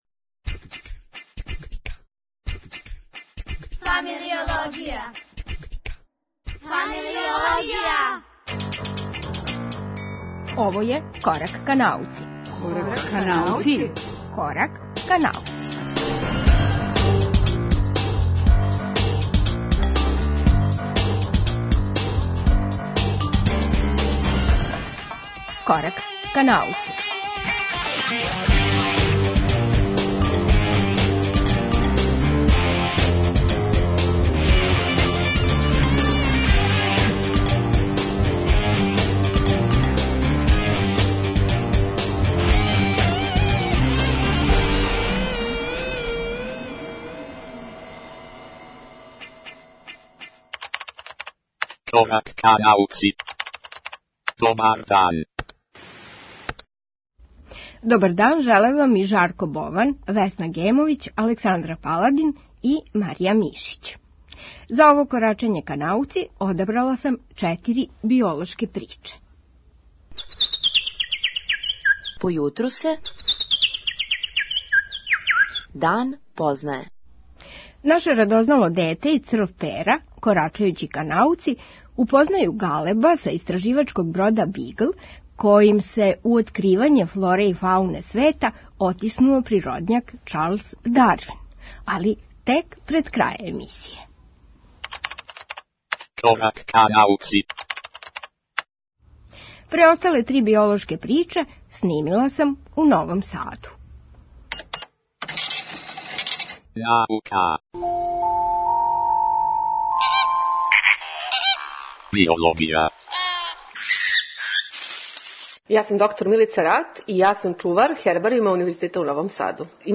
Овај Корак ка науци чини неколико сличица из Новог Сада и околине: разгледање Хербара Андрије Волног који се чува у Карловачкој гимназији, наше најстарије ботаничке збирке, и разговори с младима који закорачују ка науци.